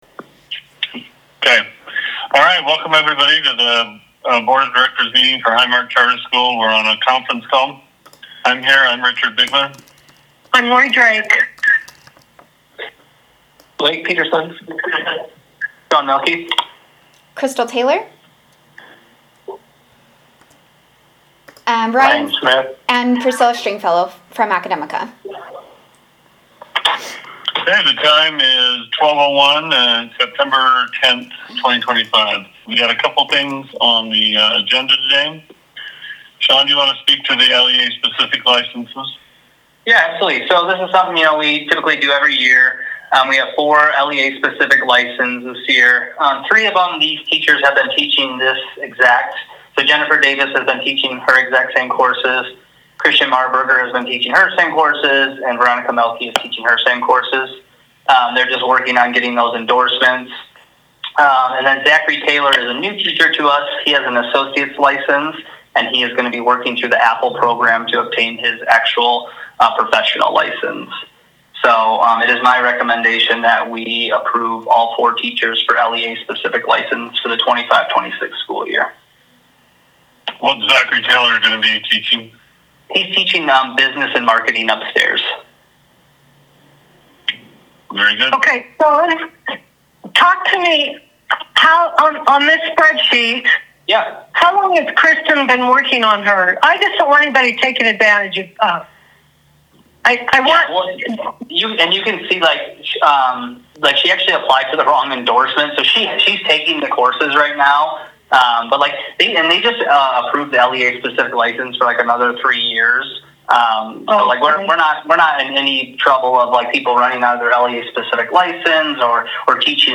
HMCS Board Meeting Electronic Phone Call